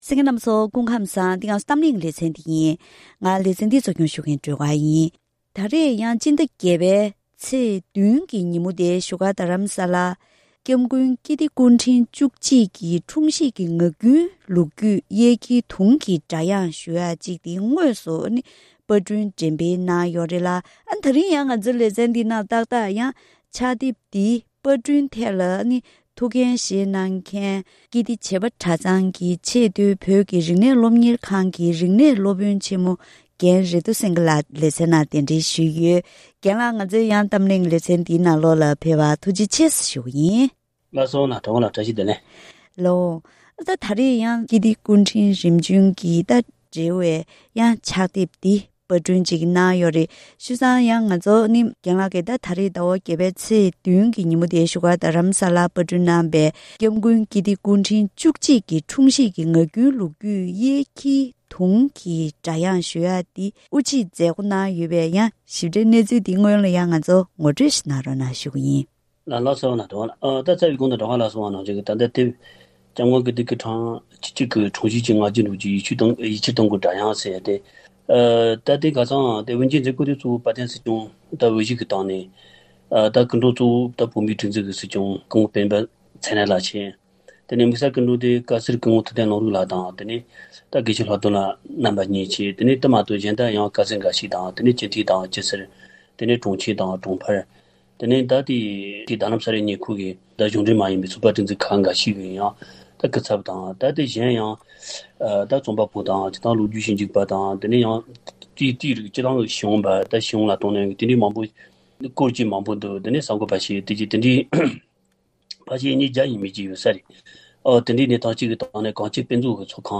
གཏམ་གླེང་ལེ་ཚན